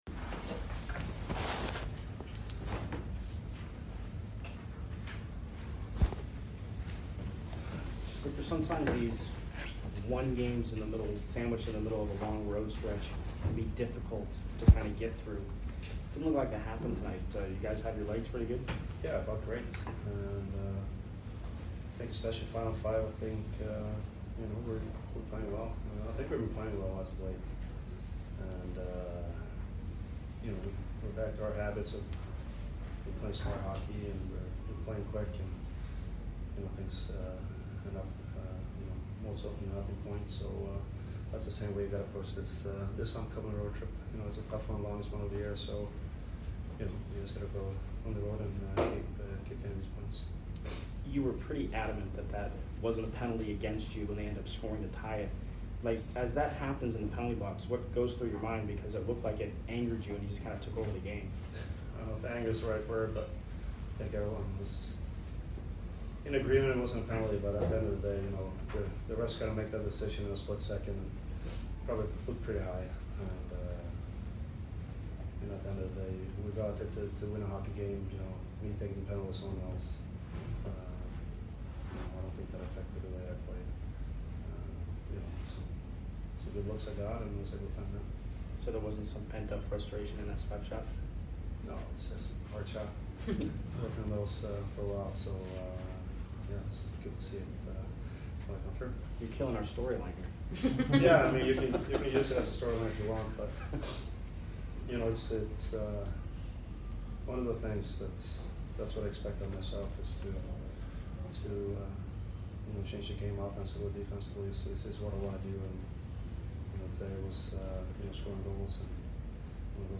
Victor Hedman Post Game Vs STL 12 - 2-21